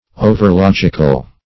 overlogical - definition of overlogical - synonyms, pronunciation, spelling from Free Dictionary
Search Result for " overlogical" : The Collaborative International Dictionary of English v.0.48: Overlogical \O"ver*log"ic*al\, a. Excessively logical; adhering too closely to the forms or rules of logic.